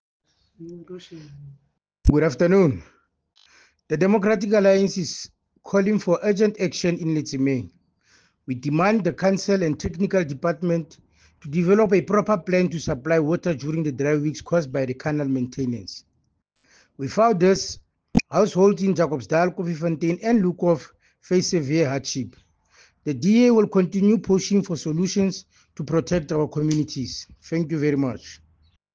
English soundbite by Cllr Thabo Nthapo and